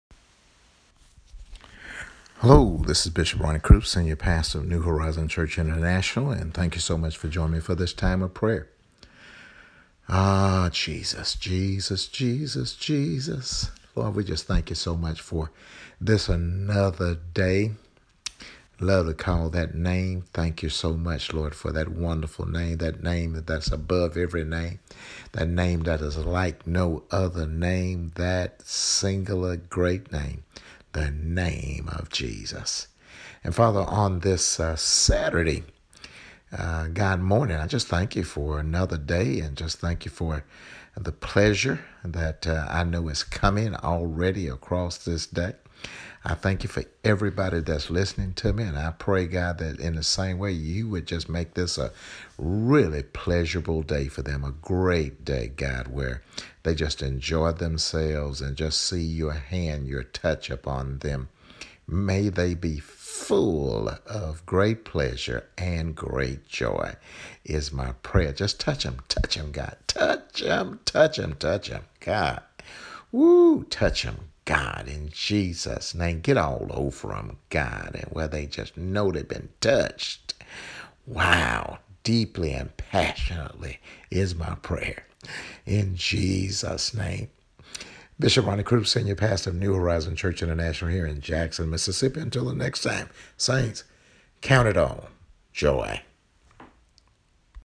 A Daily Prayer